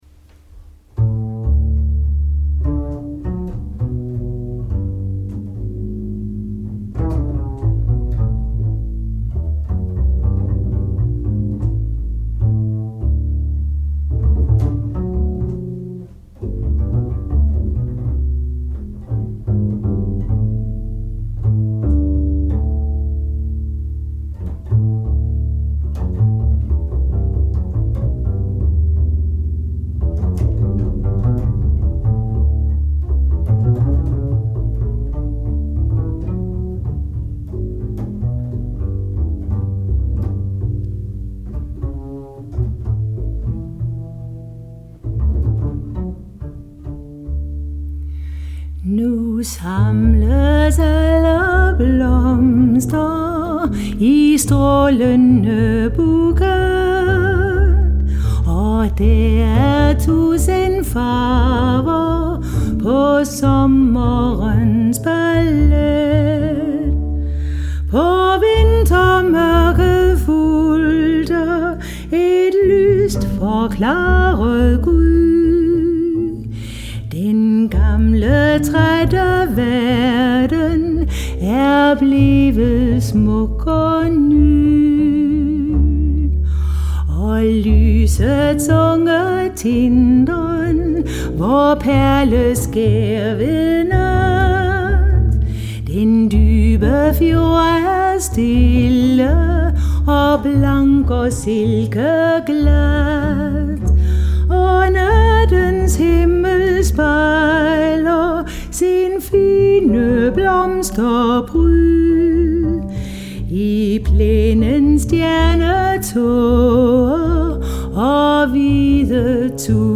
Jazz og Spirituals.
piano